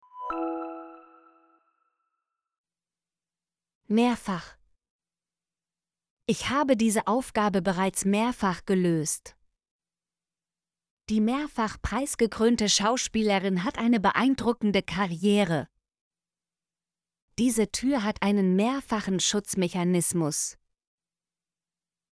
mehrfach - Example Sentences & Pronunciation, German Frequency List